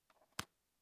Close Case.wav